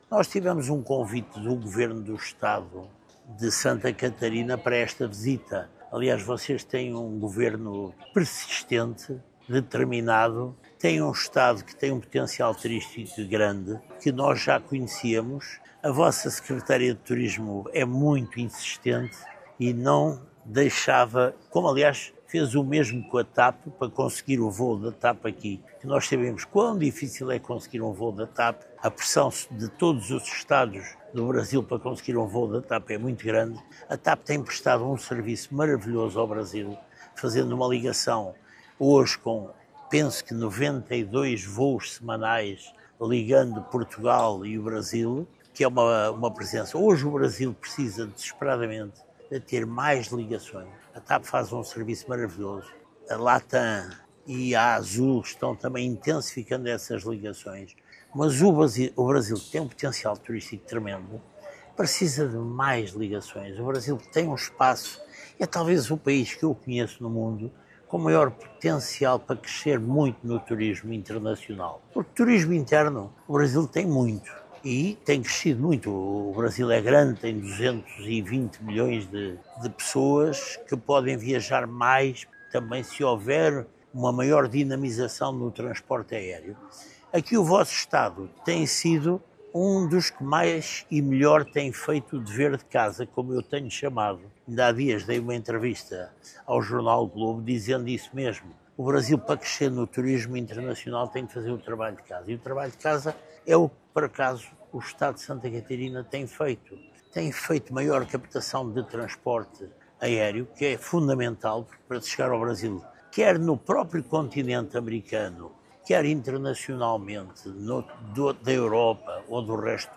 A secretária de Estado do Turismo, Catiane Seif, lembra que o interesse do grupo Vila Galé no estado é fruto de um trabalho que vem desde o início do governo Jorginho Mello, com atenção e investimentos para potencializar o fluxo de visitas em Santa Catarina:
O presidente da InvestSc, Renato Lacerda, explica o trabalho realizado para atrair o grupo português para o estado: